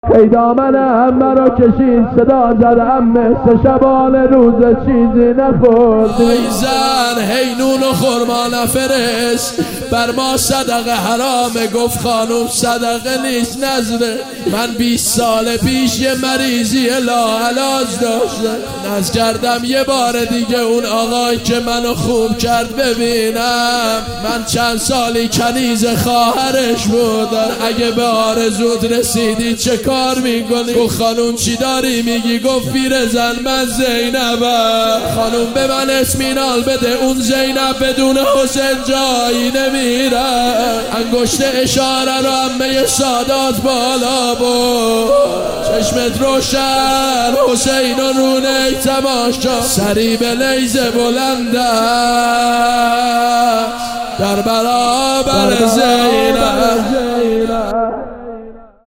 روضه جانسوز
محرم 1400